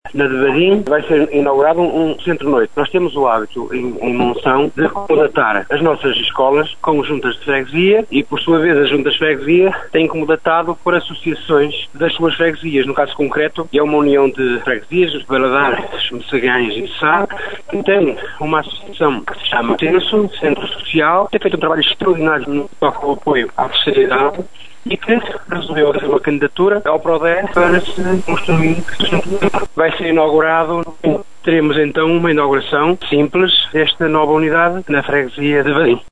O presidente da Câmara de Monção, Augusto Domingues, explica que é política da autarquia passar os edifícios das antigas escolas primárias entretanto desactivadas para as mãos das Juntas de Freguesia, que depois encontram nas instituições locais os parceiros certos para rentabilizar aquelas estruturas.
monçao-transformação-escolas-augusto-domingues-1.mp3